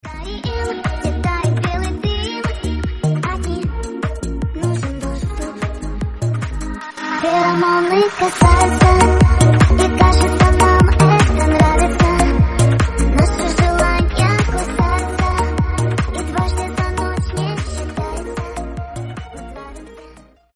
поп
попса
детский голос
цикличные